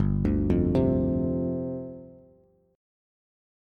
G7sus4 Chord